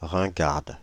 Ääntäminen
Ääntäminen France (Île-de-France): IPA: /ʁɛ̃.ɡaʁd/ Haettu sana löytyi näillä lähdekielillä: ranska Käännöksiä ei löytynyt valitulle kohdekielelle.